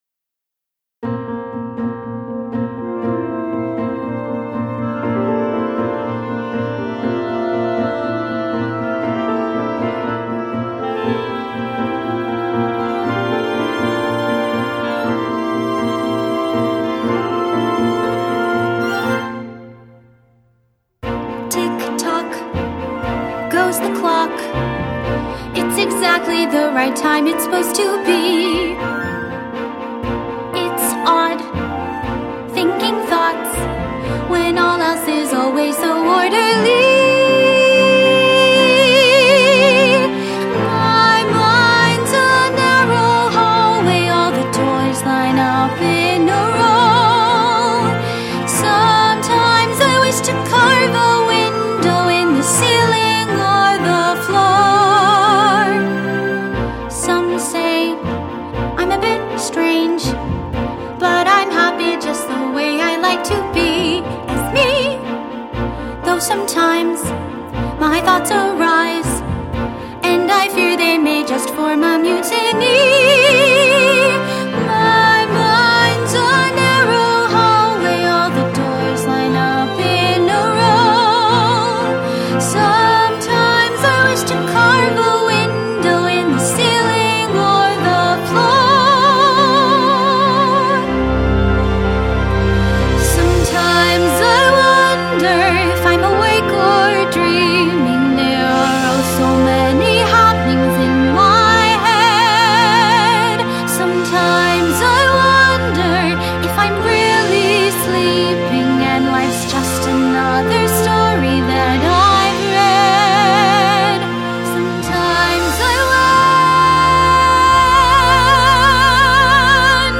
A NEW MUSICAL